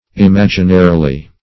Search Result for " imaginarily" : The Collaborative International Dictionary of English v.0.48: Imaginarily \Im*ag"i*na*ri*ly\, a. In a imaginary manner; in imagination.
imaginarily.mp3